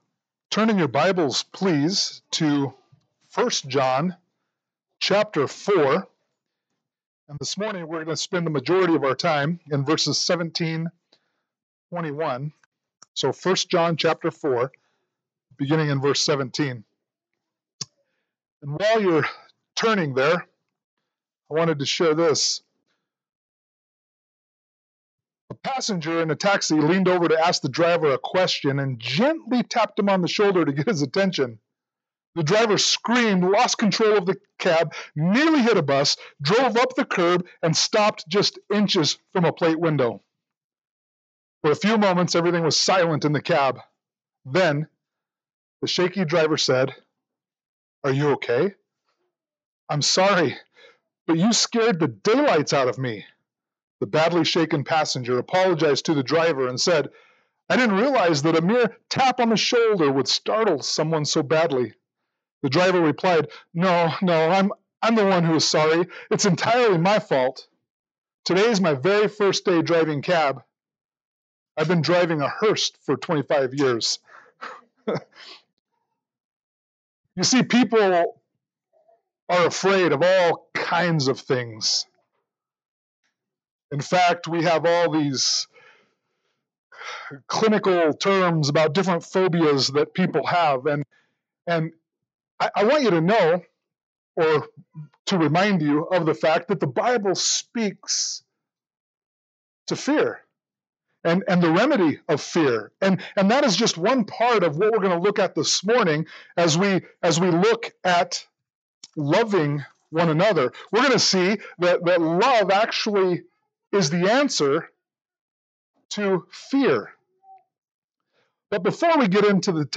1 John 4:17-21 Service Type: Sunday Morning Worship « 1 John 4:7-16 1 John 5:1-5